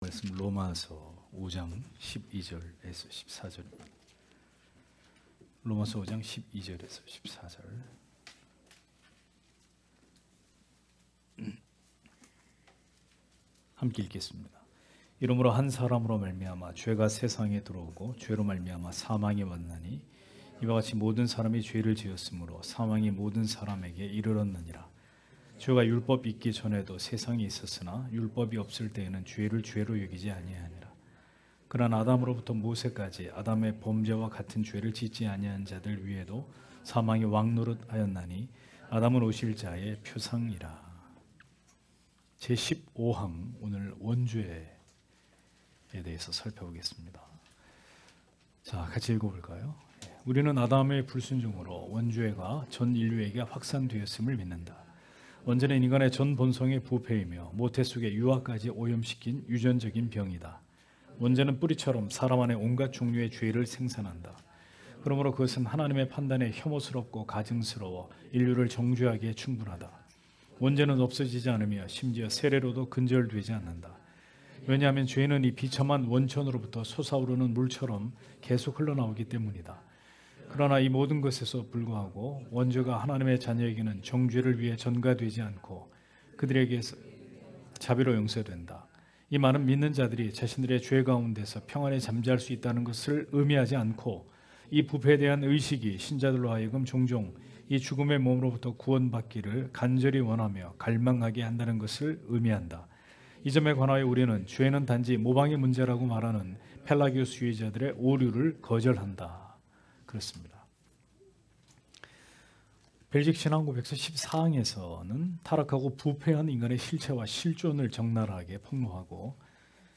주일오후예배 - [벨직 신앙고백서 해설 16] 제15항 원죄 (롬 5장12-14절)